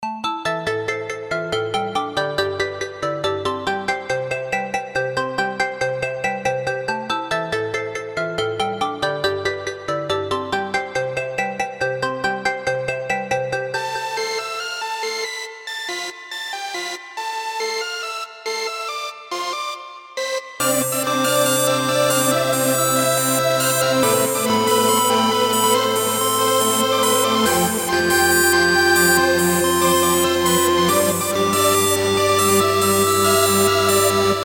Electronic Music© 2009 License: Creative Commons